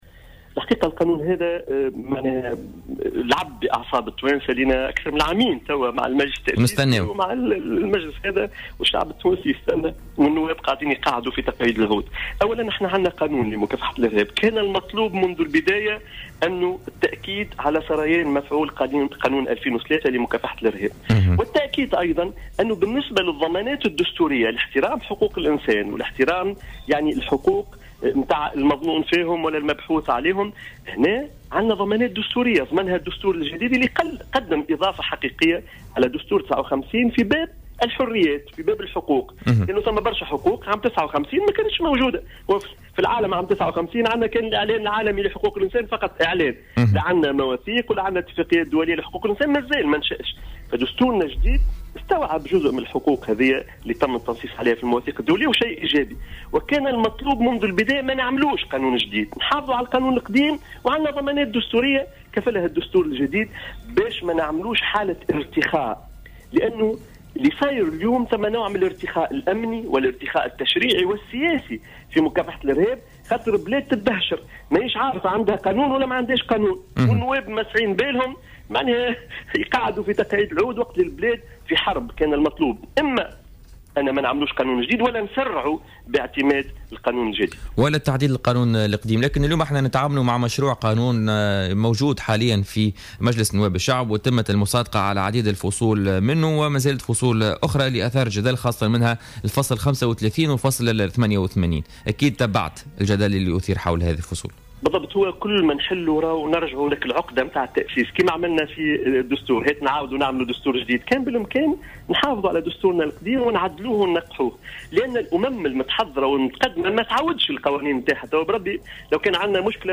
ضيف برنامج "بوليتيكا" اليوم الجمعة